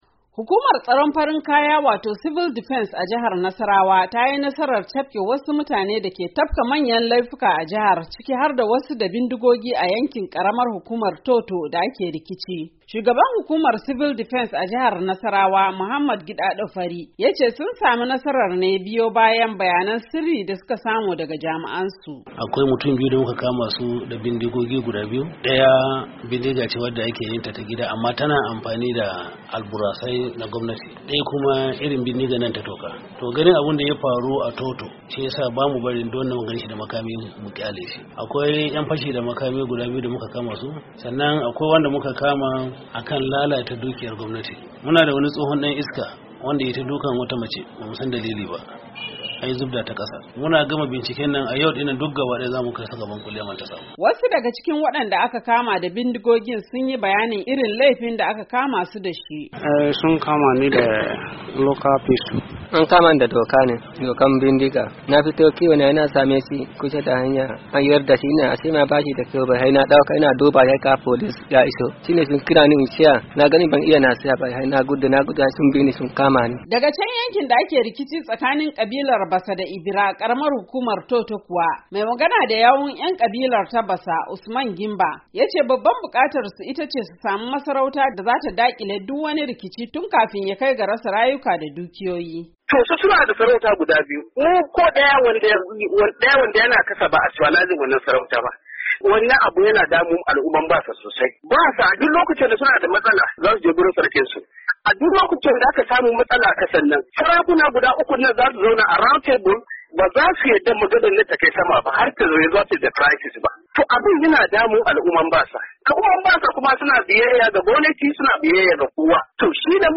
Wasu daga cikin wadanda aka Kaman sun yiwa Muryar Amurka bayanin laifukan da aka kamasu da yi.